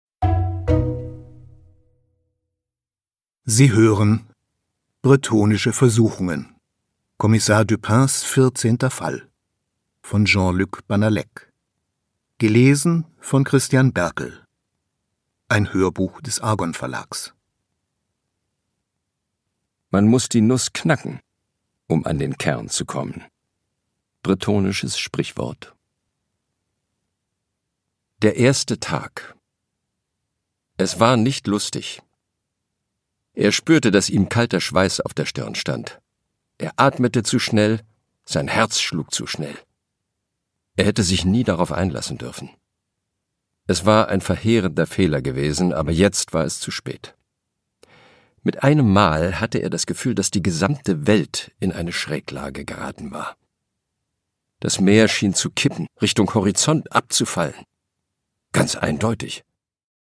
Produkttyp: Hörbuch-Download
Gelesen von: Christian Berkel